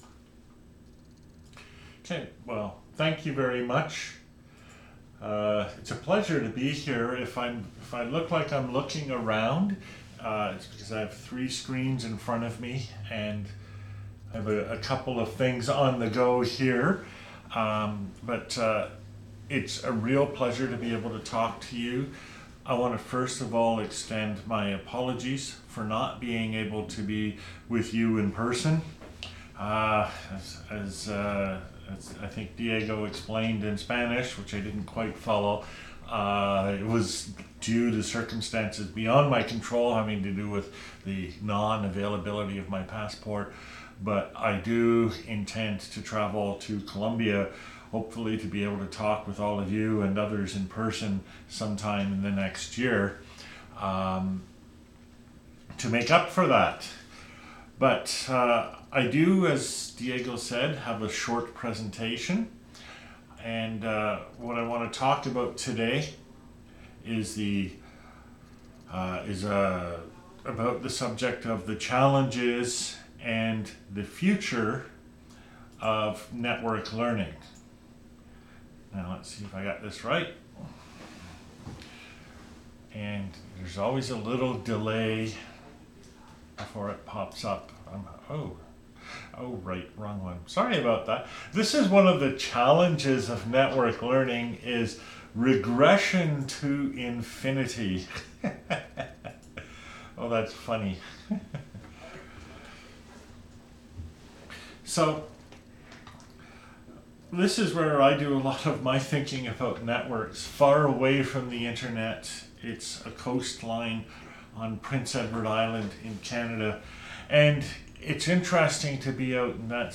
A conversation about challenges (and future?) of networked learning. A broad understanding of the meaning and potential of networked learning can help educational institutions to rethink their role beyond the provision of LMS and centralized information systems.